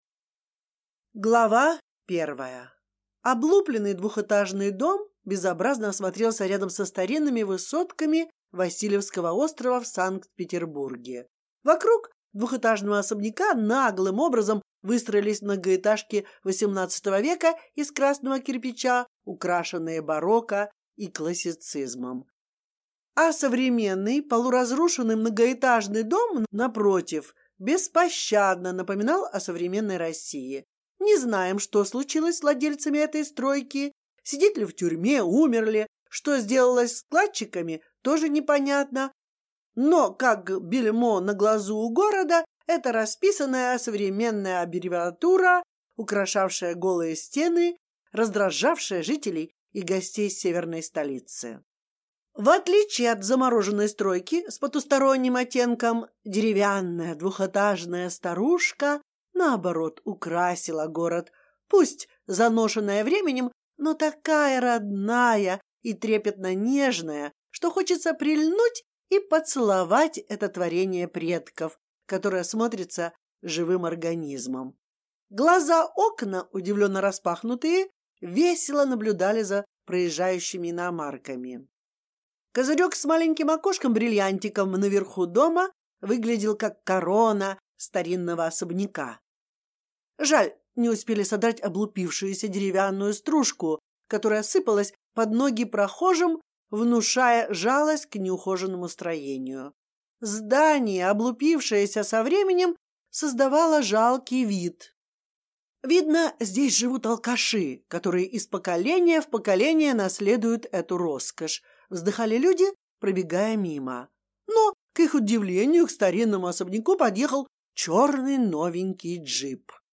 Аудиокнига Новогоднее ограбление | Библиотека аудиокниг